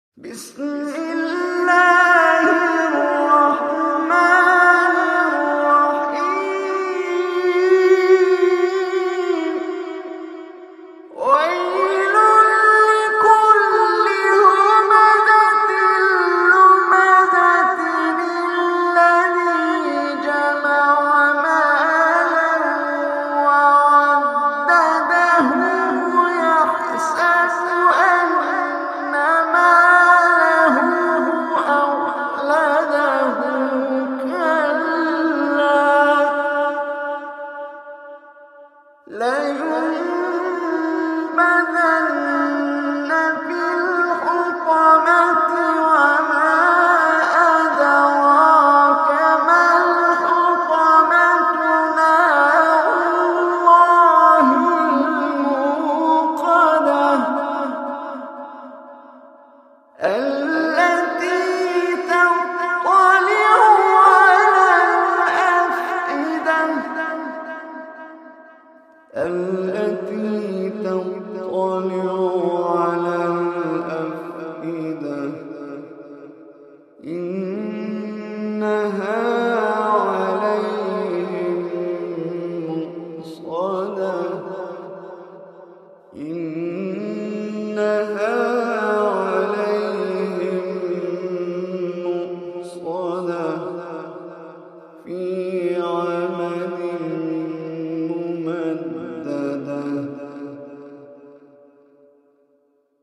Download audio recitation of Surah al-Humazah free mp3 in best audio quality.